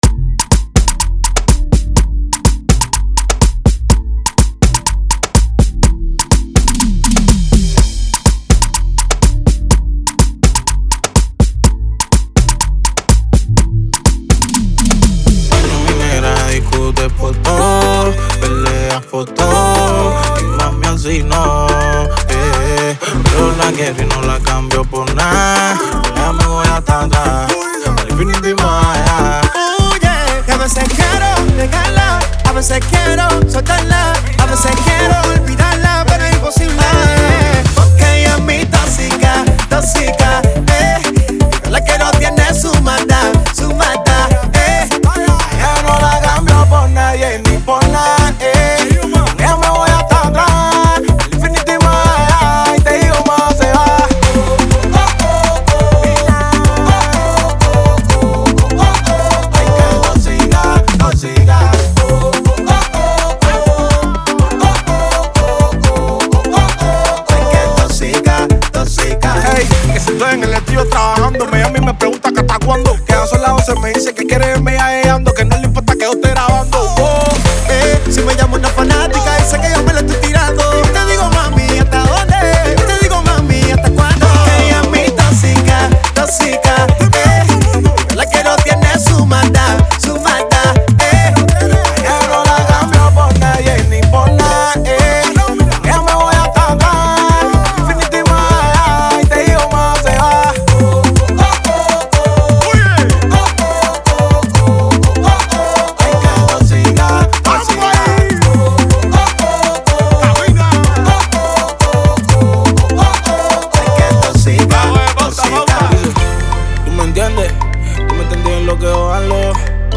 Genre: Cubaton.